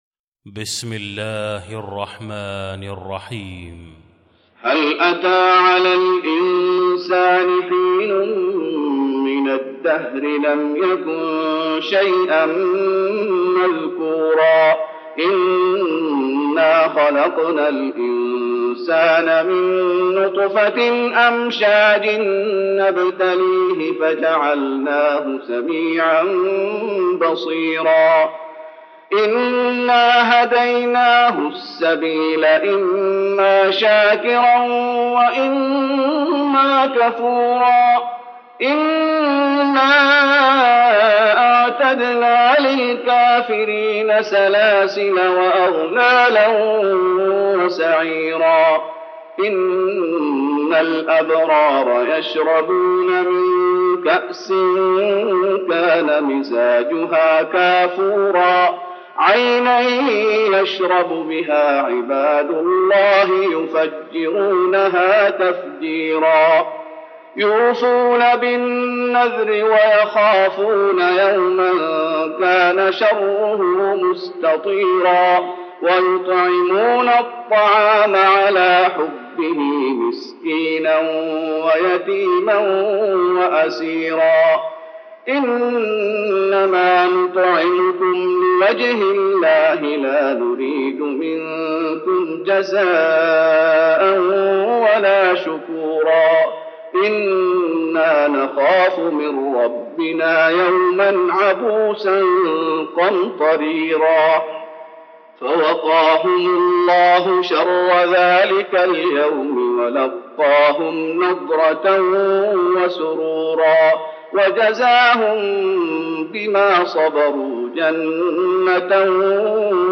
المكان: المسجد النبوي الإنسان